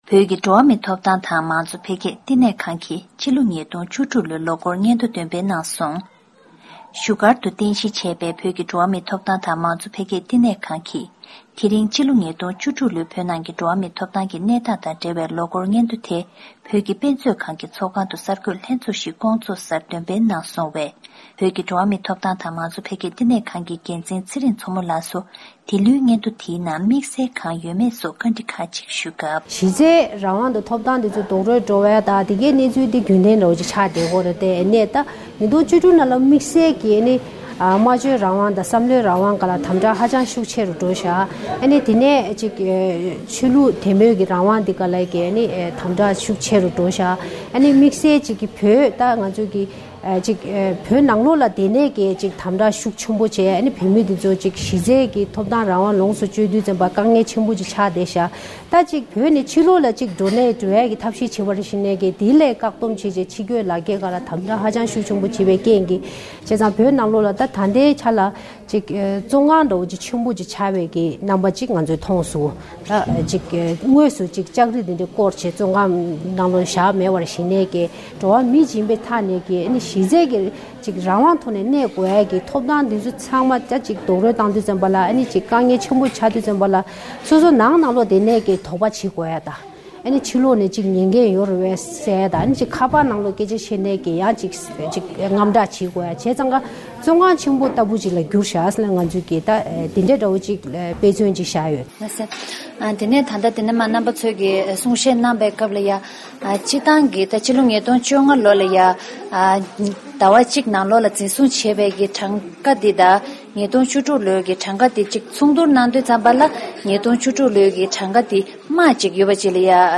The Tibetan Center for Human Rights and Democracy (TCHRD) based in Dharamsala, North India released the 2016 Annual Report on Human Rights Situation in Tibet at a press conference on February 23, 2017. In an interview with VOA